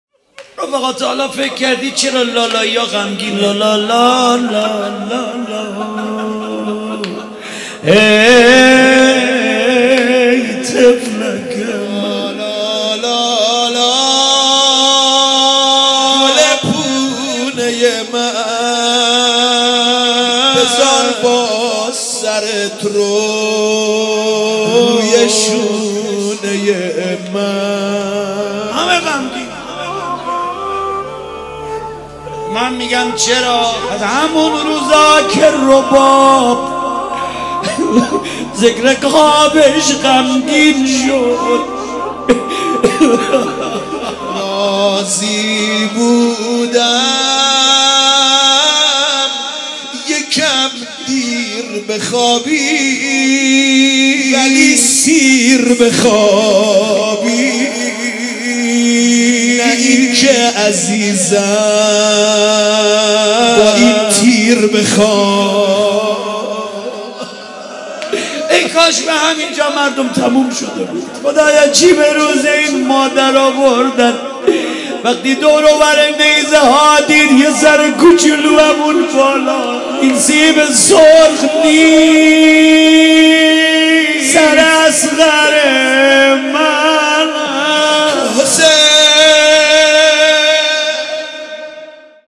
لالایی